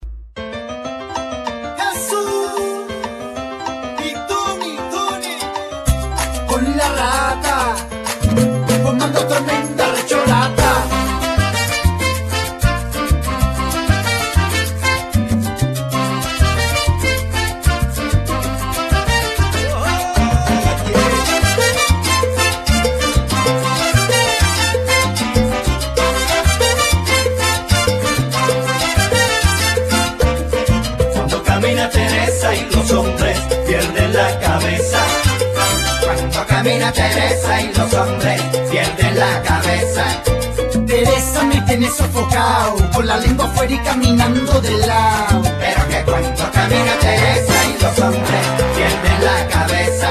Genere : Latin